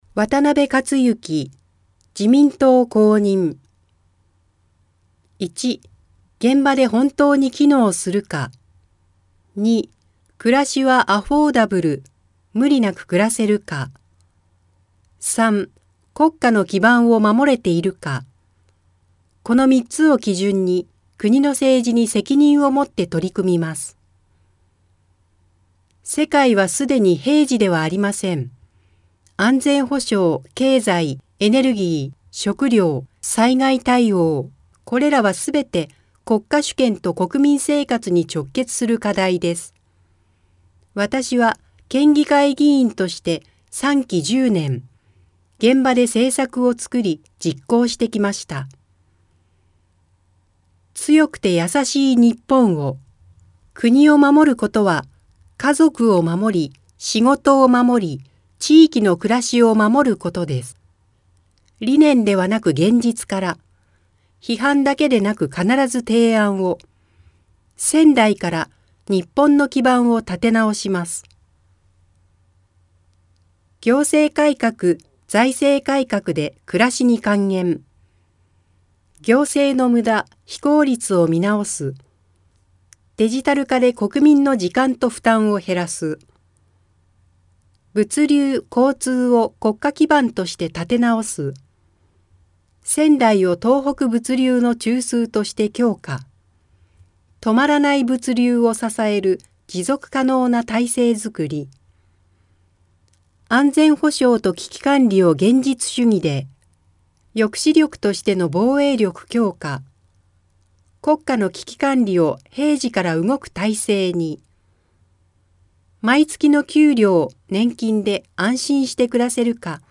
衆議院議員総選挙候補者・名簿届出政党等情報（選挙公報）（音声読み上げ用）